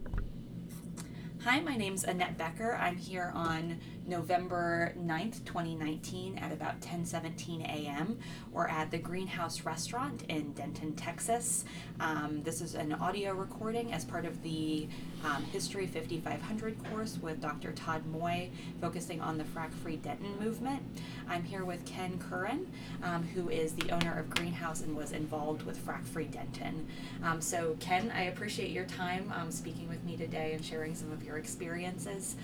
Introduction to interview